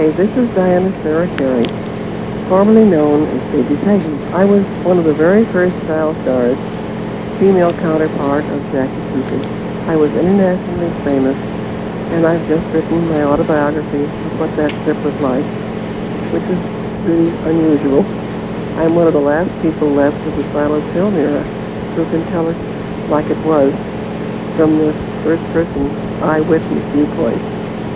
In her own voice: